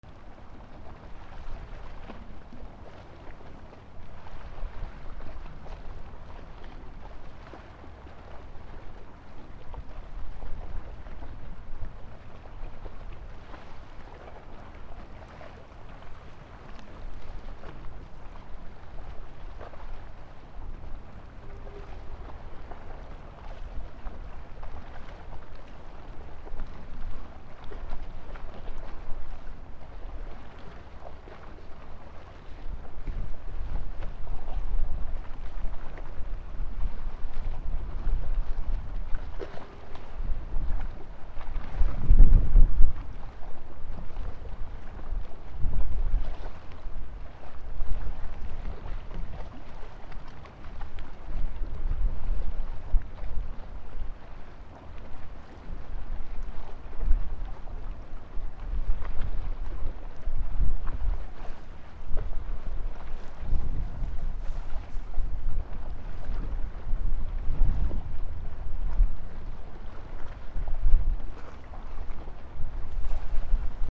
/ B｜環境音(自然) / B-10 ｜波の音 / 波の音
港 高松 オフ気味